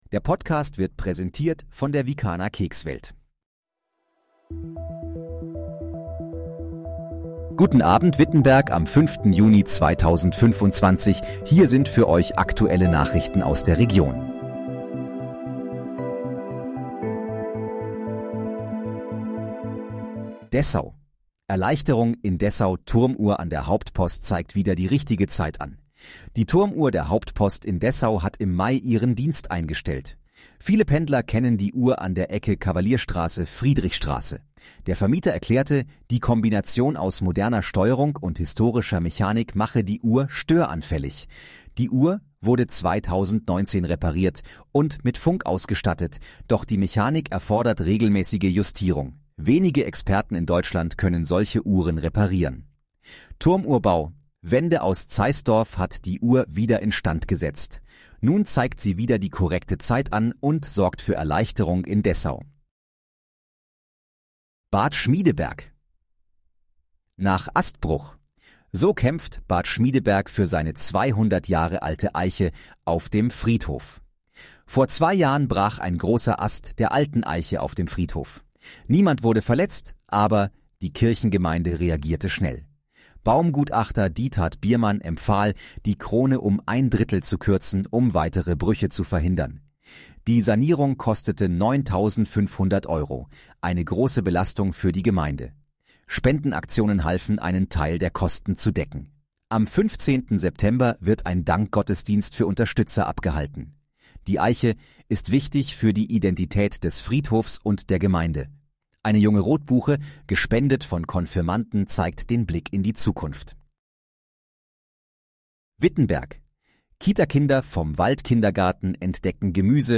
Guten Abend, Wittenberg: Aktuelle Nachrichten vom 05.06.2025, erstellt mit KI-Unterstützung
Nachrichten